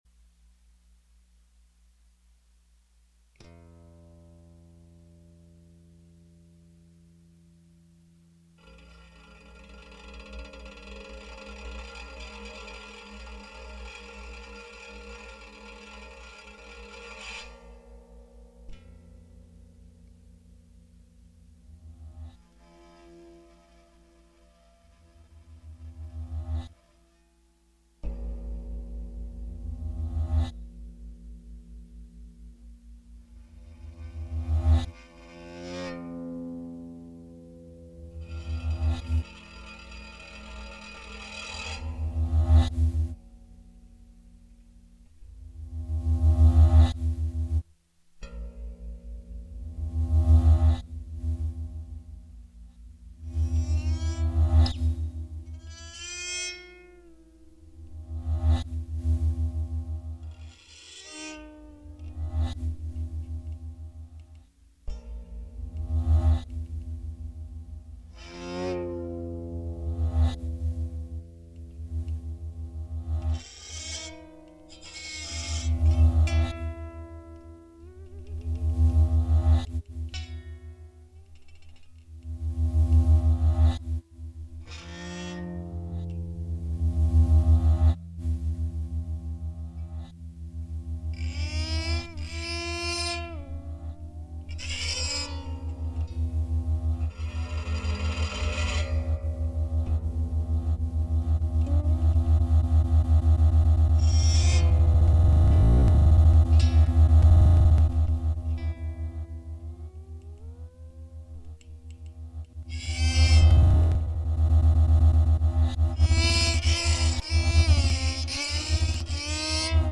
Improvisation (2014) for zithryan and electronics Listen: (Stereo | 6-channel) Sorry.
electronics, live sampling
zythrian Program Recorded by the Dept. of Performance Studies at Texas A&M University.